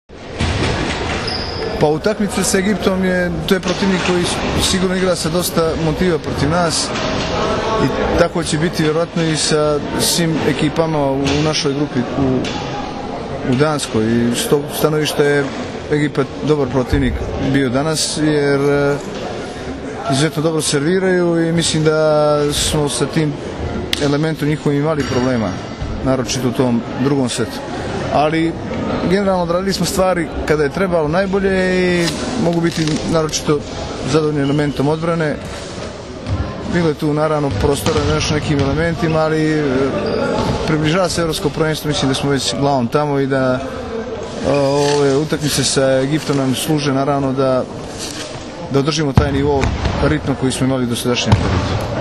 IZJAVA IGORA KOLAKOVIĆA, SELEKTORA SRBIJE